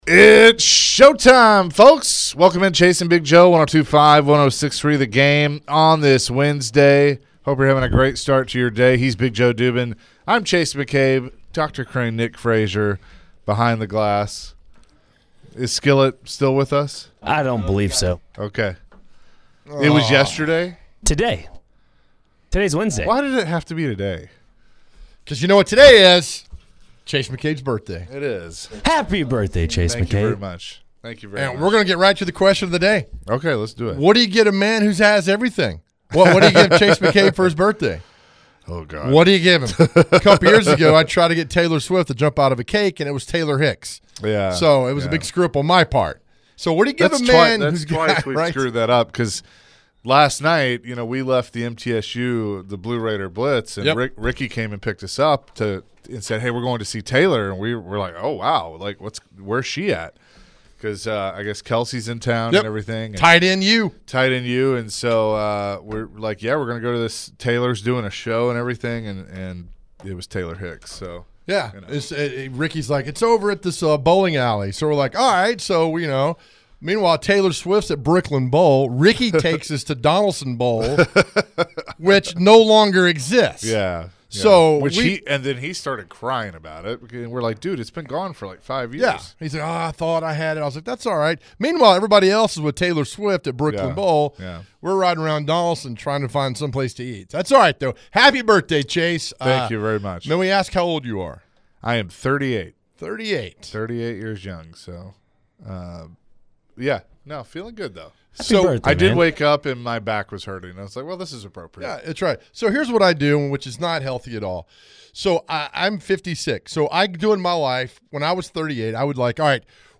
To end the hour, callers and texters sent in their best gift ...